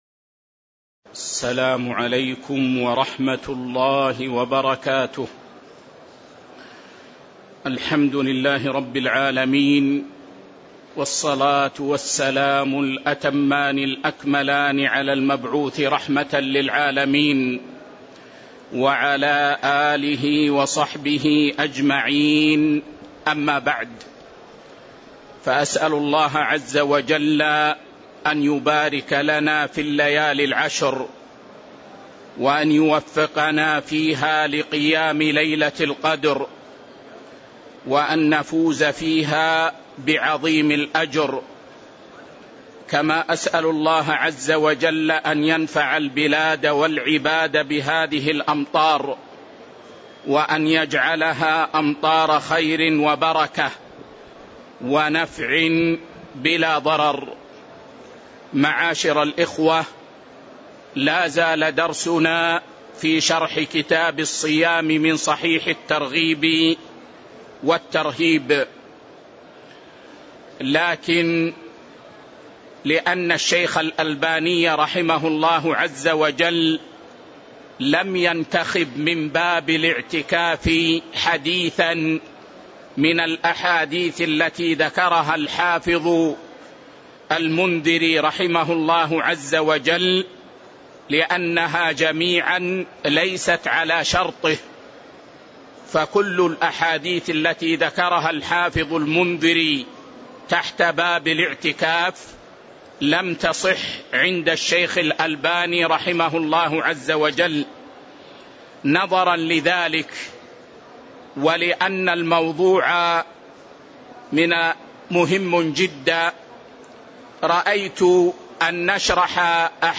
شرح صحيح الترغيب والترهيب 1 الدرس 97 كتاب الصيام 13 كتاب الأعتكاف من عمدة الأحكام